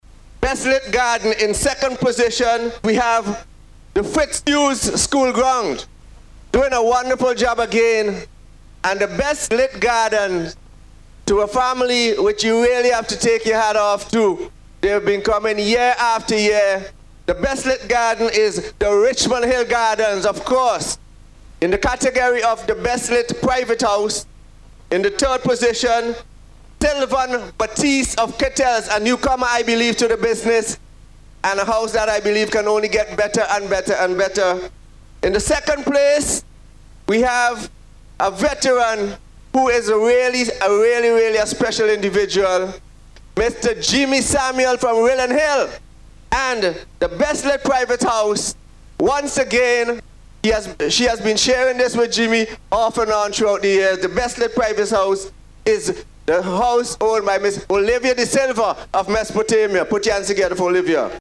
The results were announced this morning in Kingstown at the official close of the 2015 Nine Mornings festivities